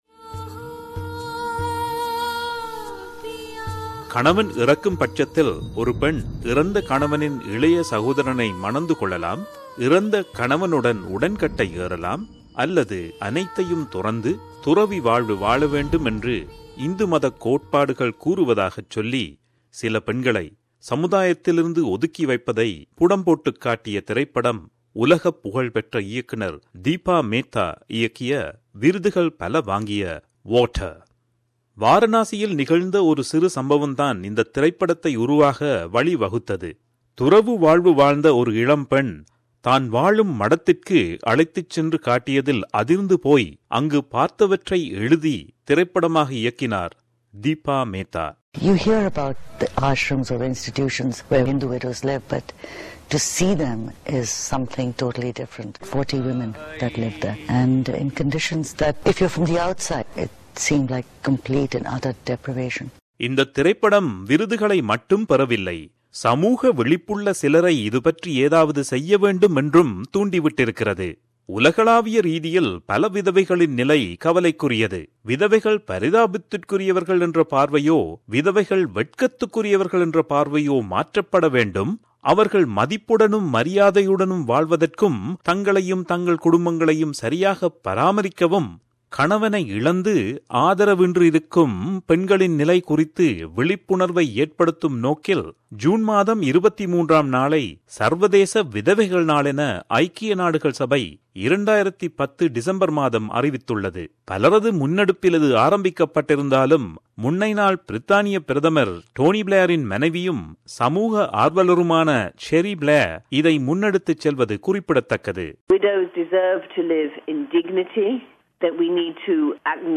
ஒலி சித்திரம்